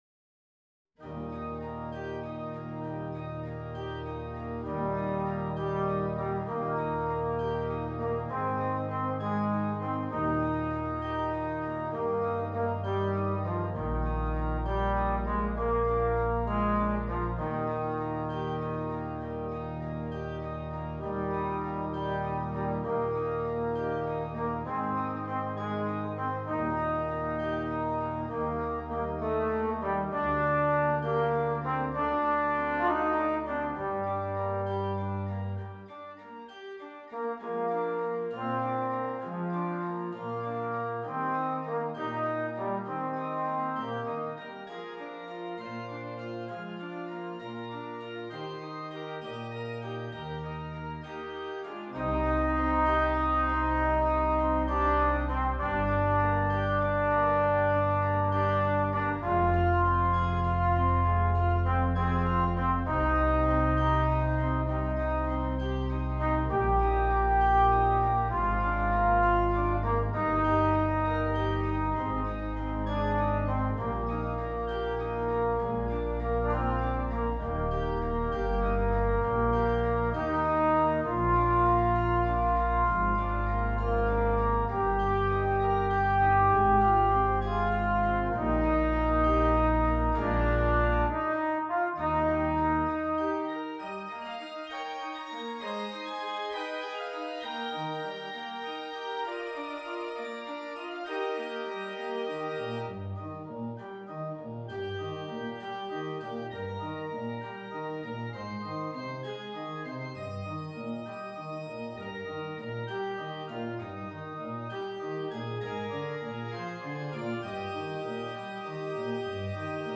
Christmas
Trombone and Keyboard